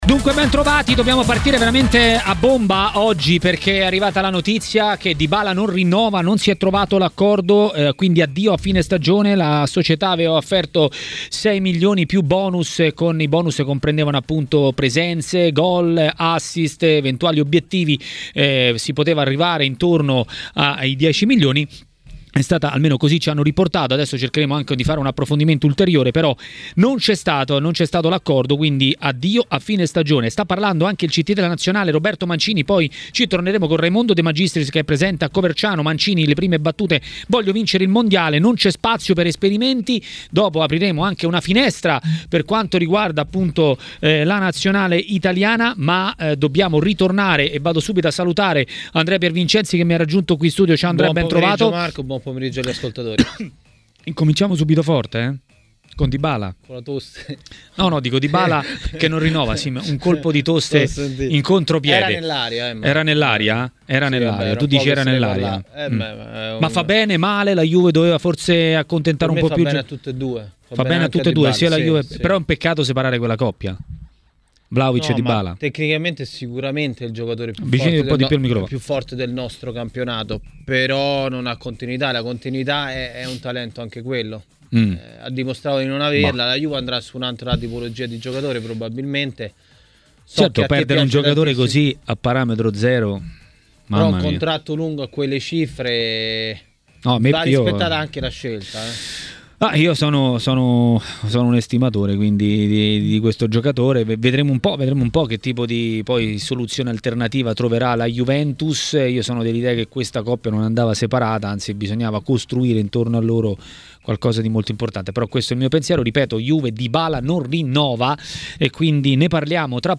A Maracanà, nel pomeriggio di TMW Radio